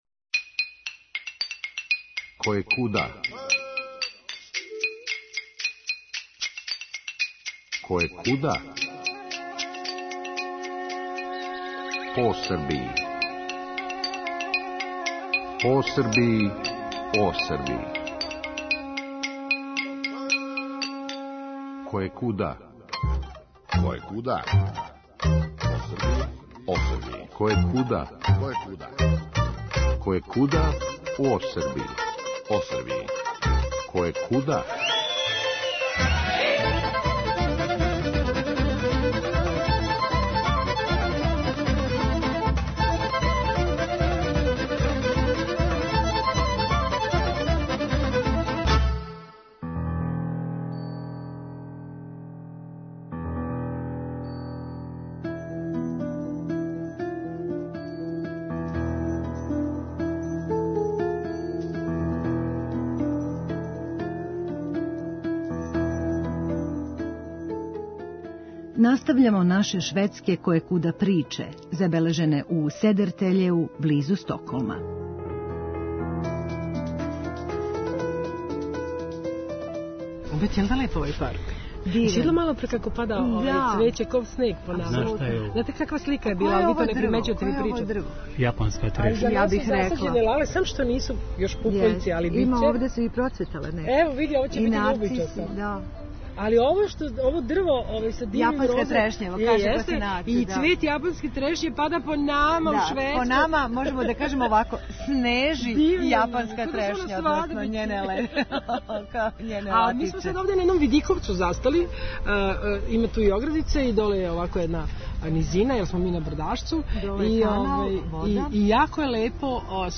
Настављамо наше шведске "којекуда" приче и разговор